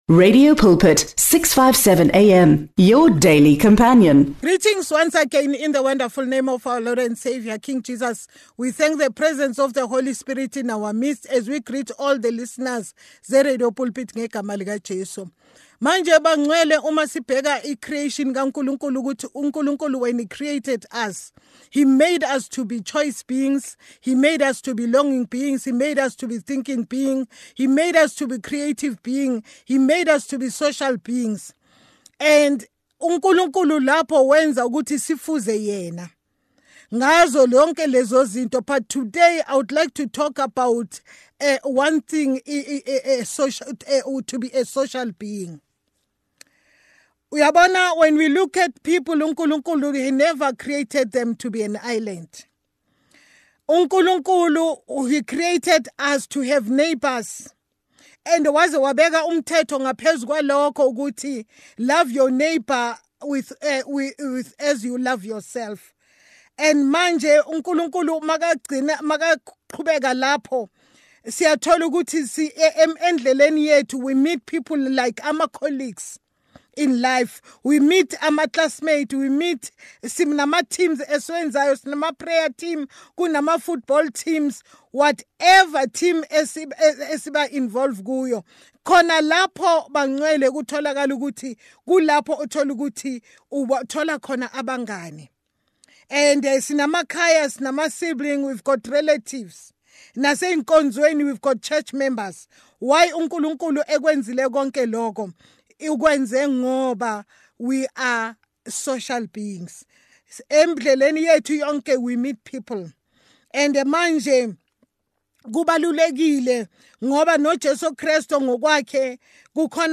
Listeners can expect a fresh Word from God early in the morning every weekday. Pastors from different denominations join us to teach the Word of God.